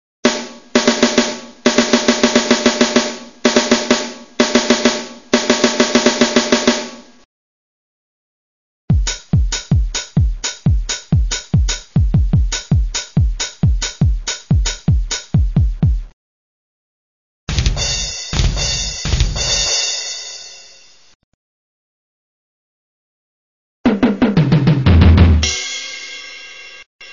I've made this cute software, that lets you drum on your keyboard, but generate real drums sounds!
Please note the quality was decreased, and it's my lame drumming skills you hear...
WinPDrumsDemo.mp3